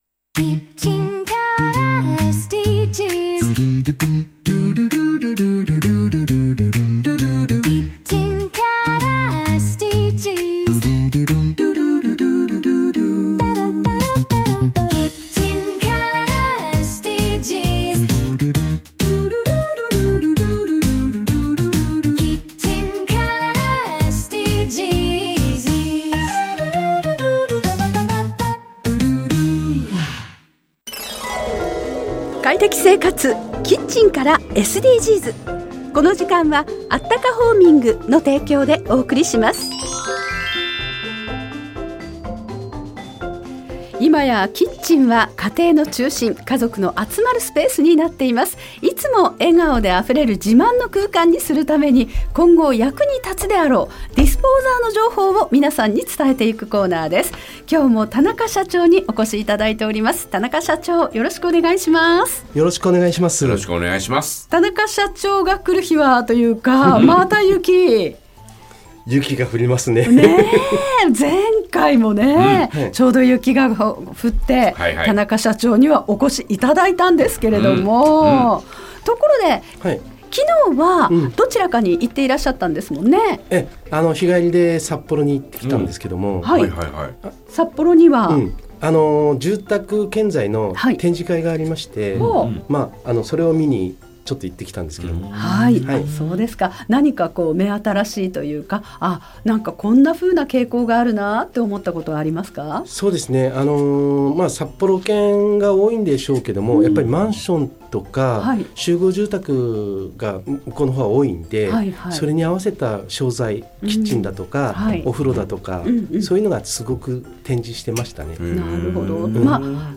【ラジオ】快適生活 キッチンからSDGs 放送中 （ じゃらら（JAGA）10時30分～45分 ）